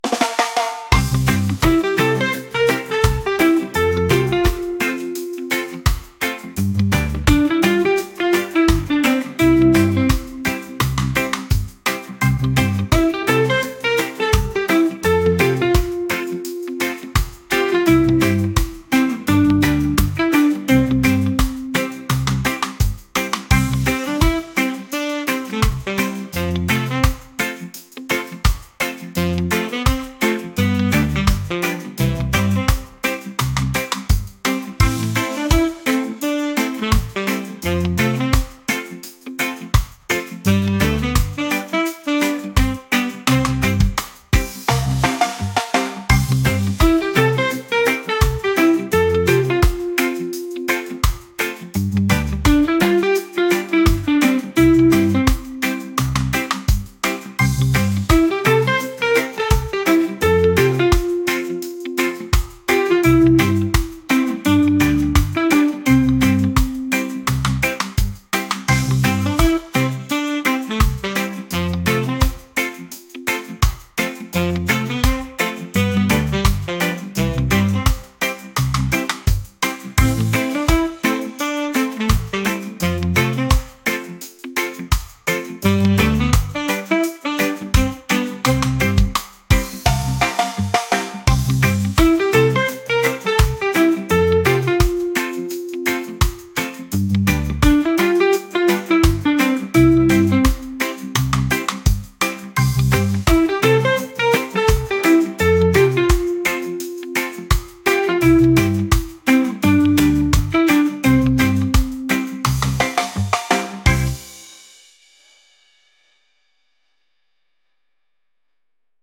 reggae | funk | pop